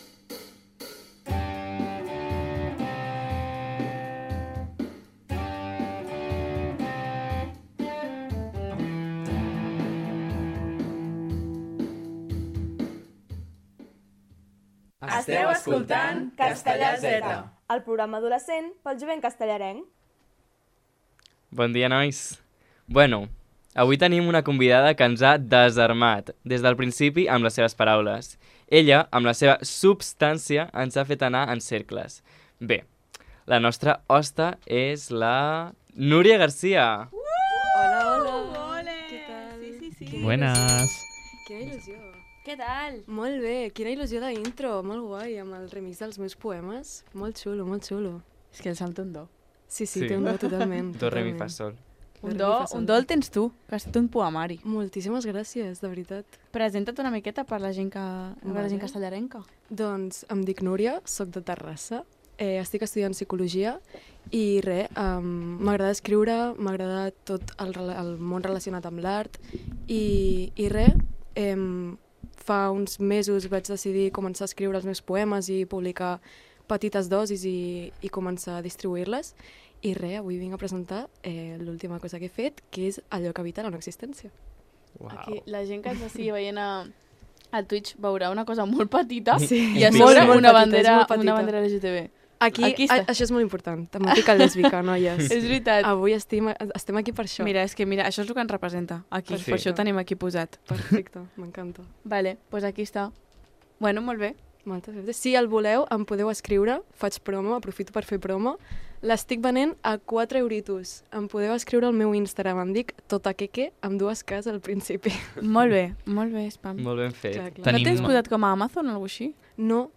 Infantil-juvenil